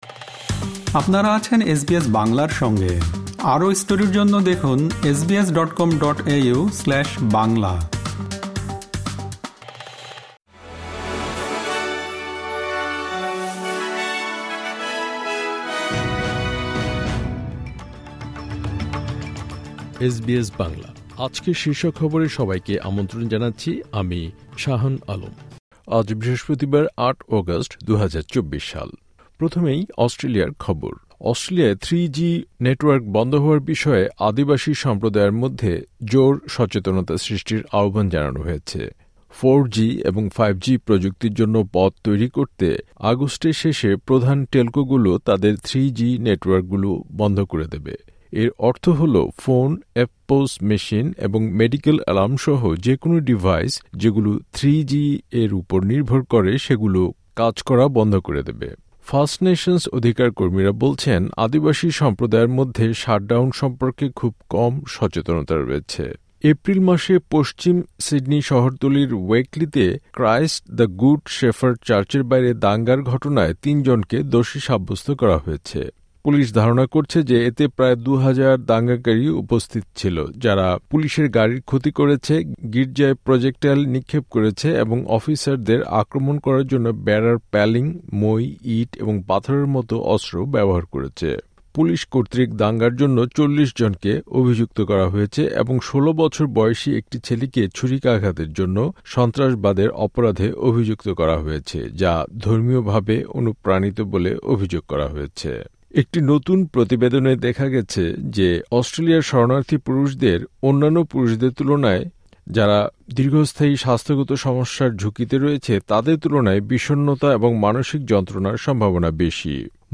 এসবিএস বাংলা শীর্ষ খবর: ৮ অগাস্ট, ২০২৪